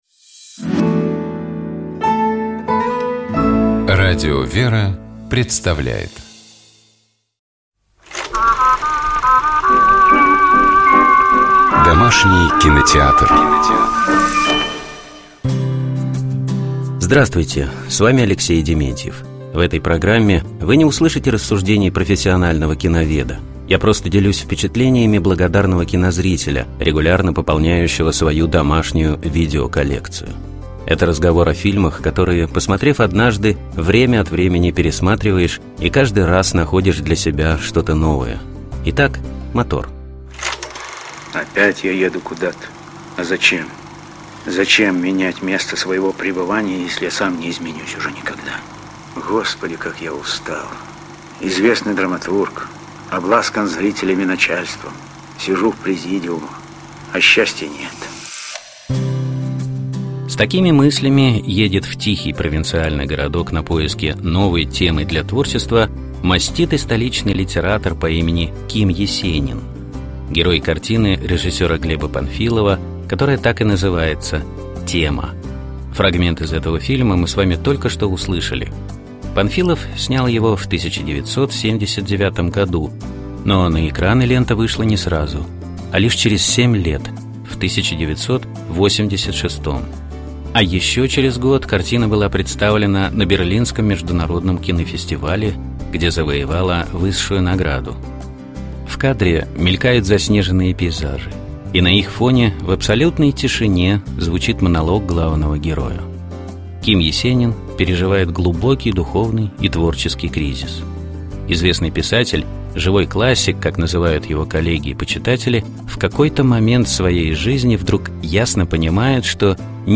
Фрагмент из этого фильма мы с вами только что услышали.
В кадре мелькают заснеженные пейзажи, и на их фоне, в абсолютной тишине, звучит монолог главного героя.
Вот почему в его голосе слышится тоска, горечь, разочарование в жизни и самом себе.